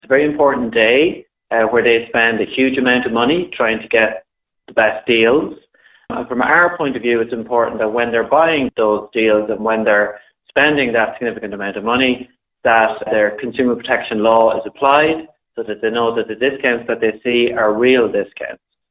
CCPC Chair, Brian McHugh says people need to get real sales bargains not fake ones.